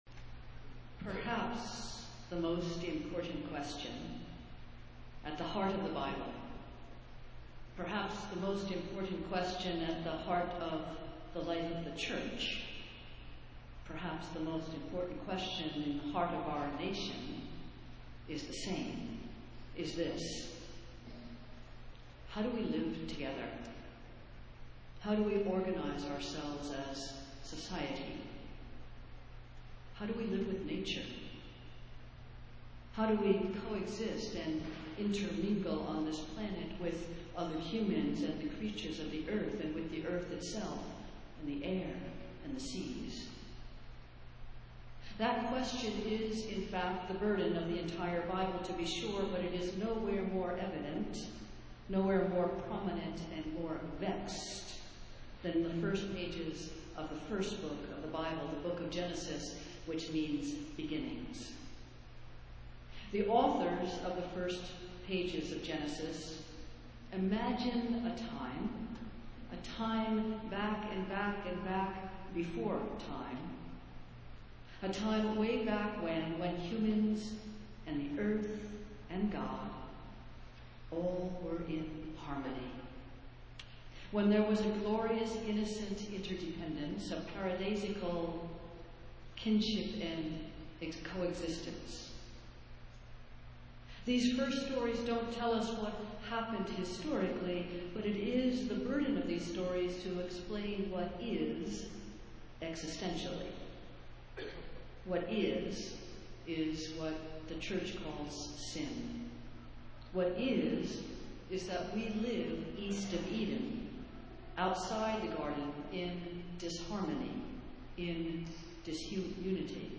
Festival Worship - Fourth Sunday after Pentecost